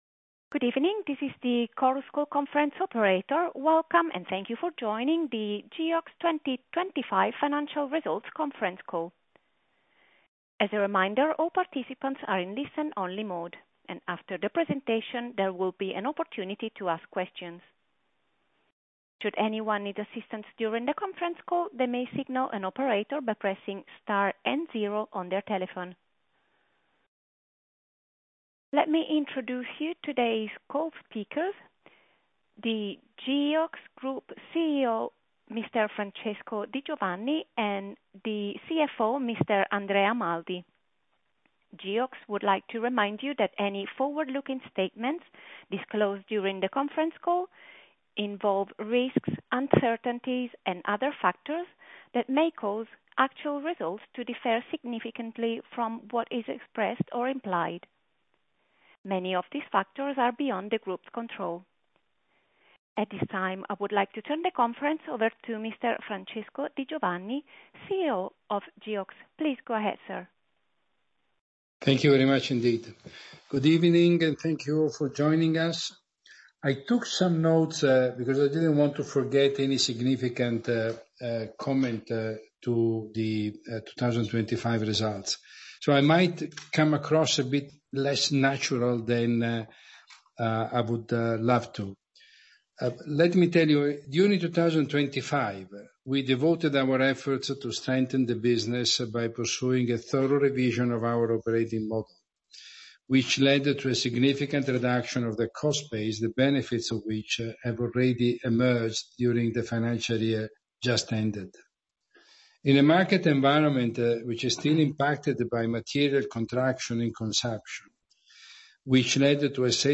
Conference Call - FY25 Financial Results